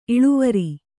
♪ iḷuvari